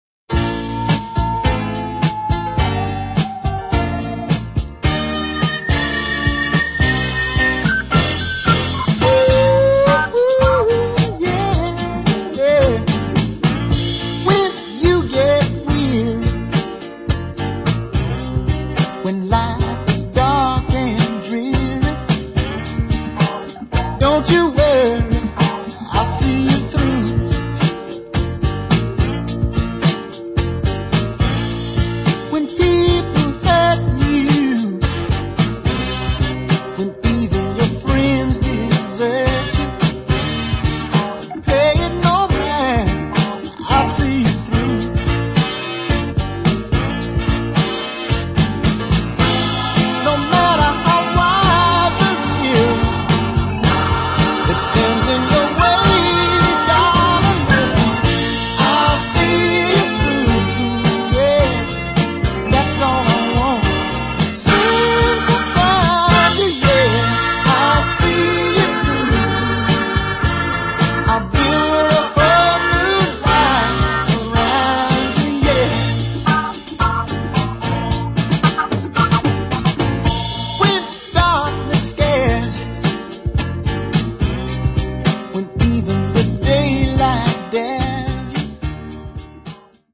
Love this Southern Soul Shuffler to bits!